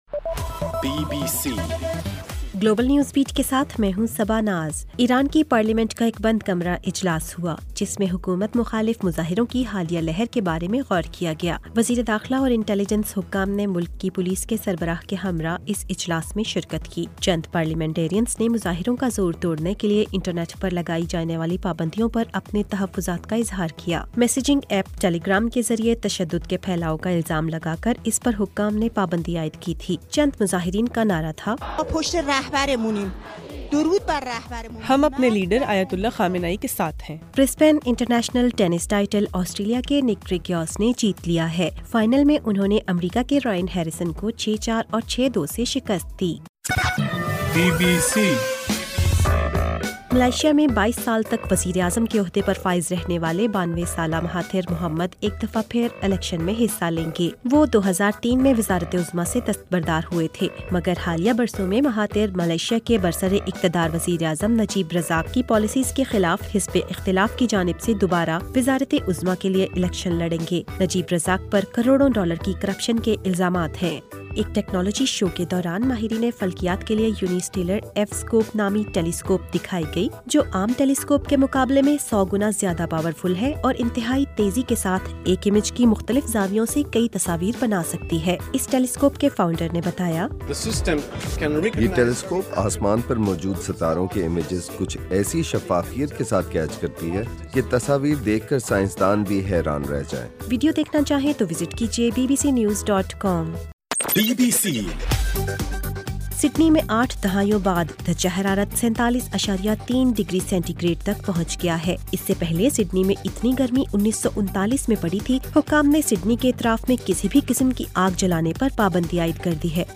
گلوبل نیوز بیٹ بُلیٹن اُردو زبان میں رات 8 بجے سے صبح 1 بجے ہرگھنٹےکے بعد اپنا اور آواز ایف ایم ریڈیو سٹیشن کے علاوہ ٹوئٹر، فیس بُک اور آڈیو بوم پر سنئیِے